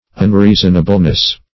Un*rea"son*a*ble*ness, n. -- Un*rea"son*a*bly, adv.